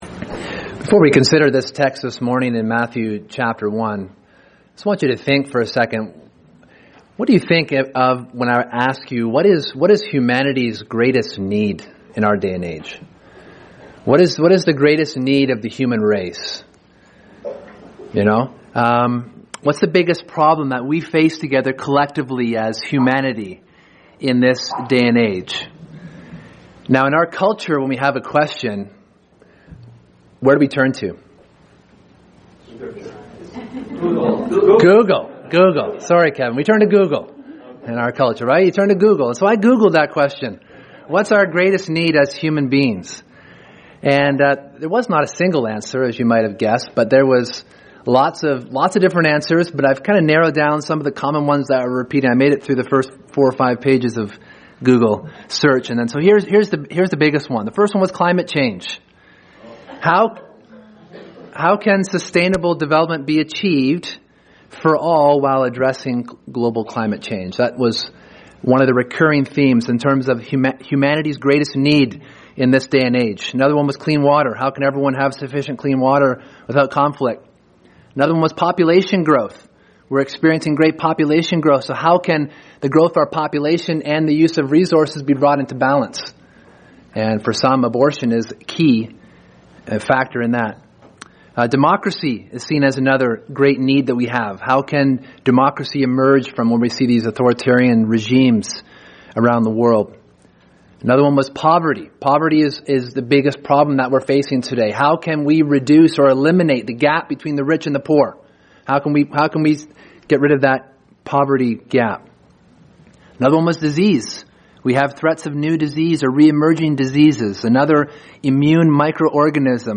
Sermon: His Name Is Jesus